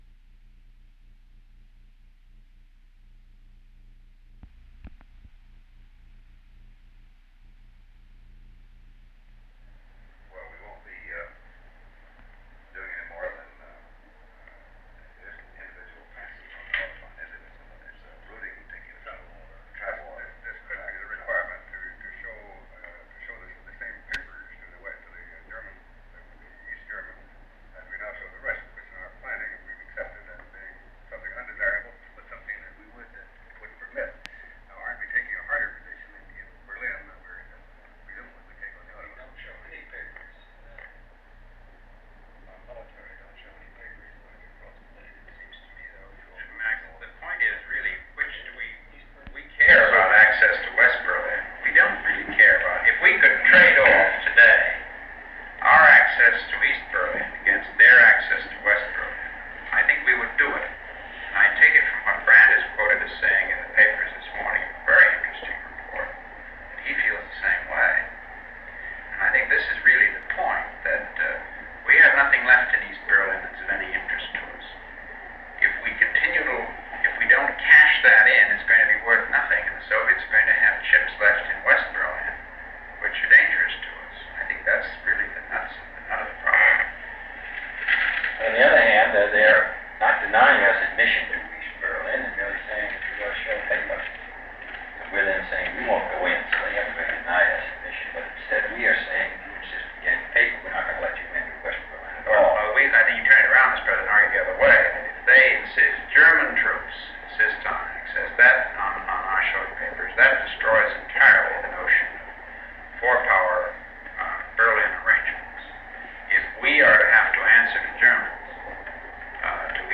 Secret White House Tapes | John F. Kennedy Presidency Meeting on Berlin Rewind 10 seconds Play/Pause Fast-forward 10 seconds 0:00 Download audio Previous Meetings: Tape 121/A57.